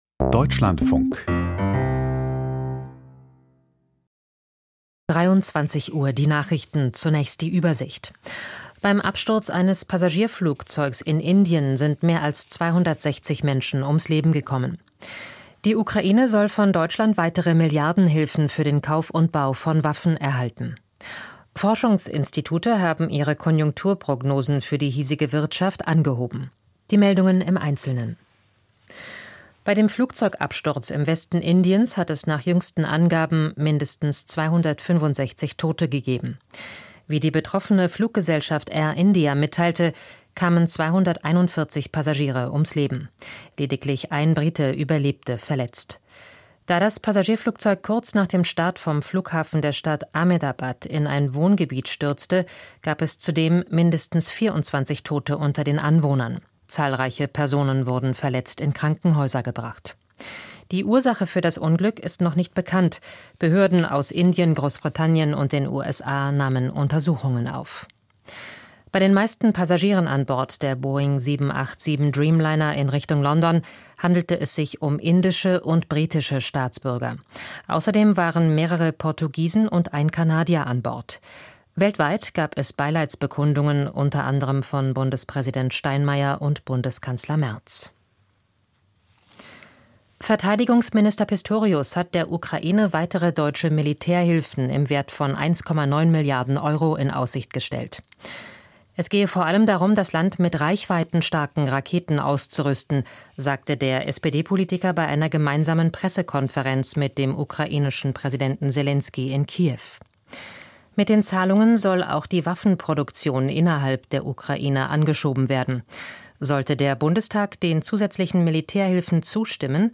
Männlichkeitsforschung: Probleme und Konsequenzen - Interview